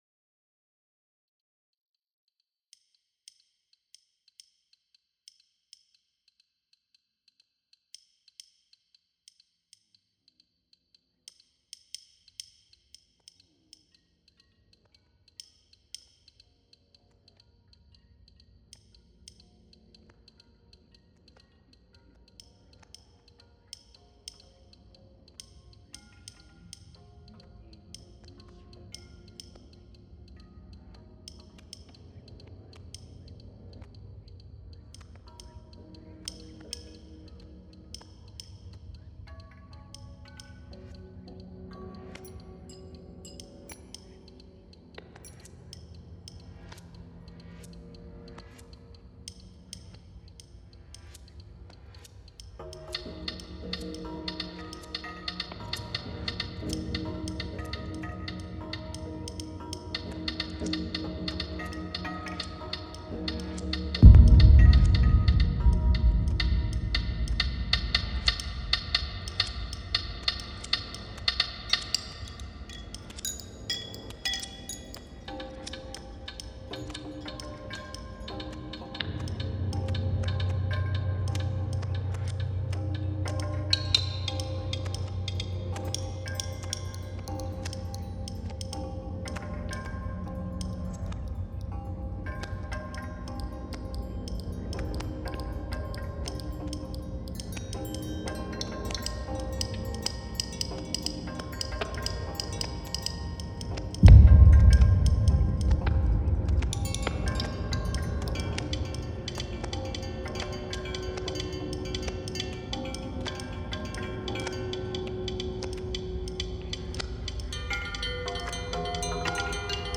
L'Antre des Pendules - Musique concrètement expérimentale
Musique expérimentale pour cloches, carillons, un gros tambour, des bruits et quelques percussions d'onomatopées métalliques et boisées.